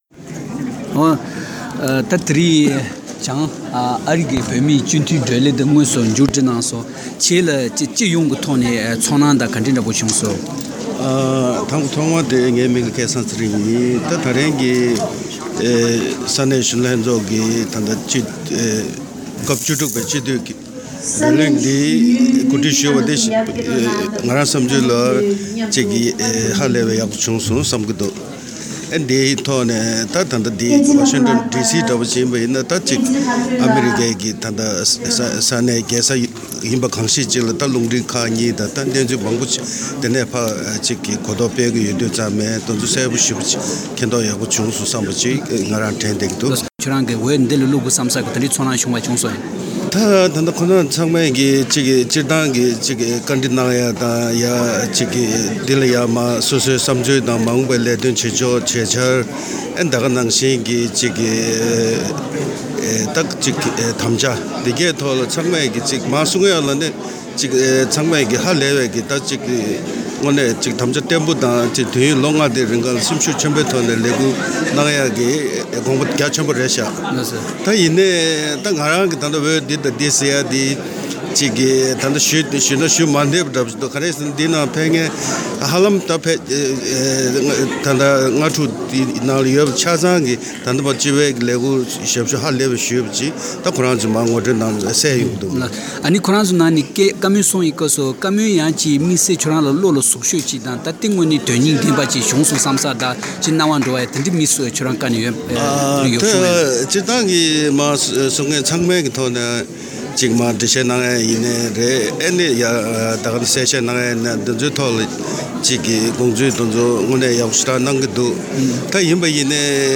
བྱང་ཨ་རིའི་སྤྱི་འཐུས་འོས་མིའི་བགྲོ་གླེང་གནང་སྐབས་སུ་མི་དམངས་ཀྱི་བསམ་ཚུལ་ཇི་ཡོད་བཅའ་འདྲི་བྱེད་པ།